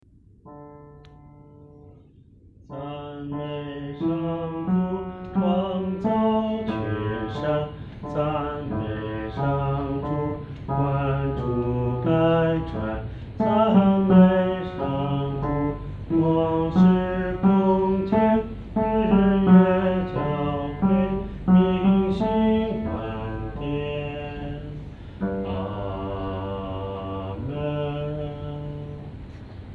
男低
这首诗的曲调活跃，和声变化色彩丰富；与前面五首赞美诗的和声处理方面很不同，是一首典型的“众赞歌”。